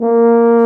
BRS TUBA F0O.wav